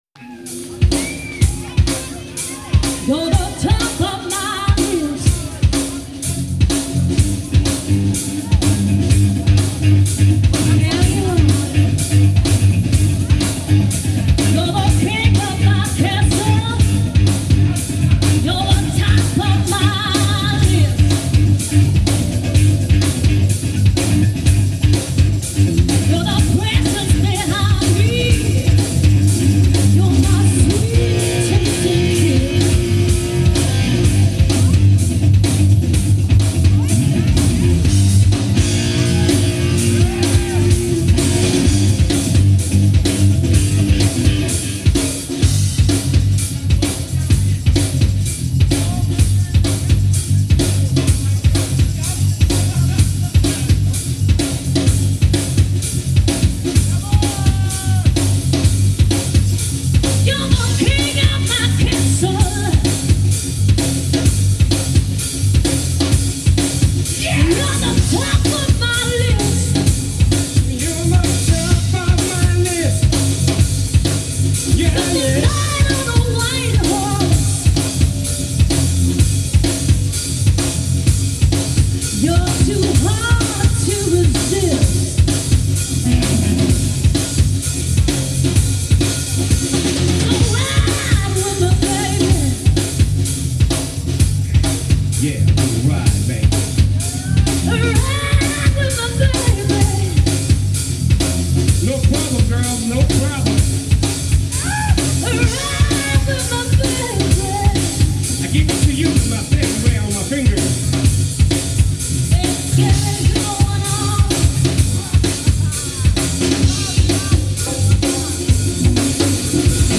live in Hannover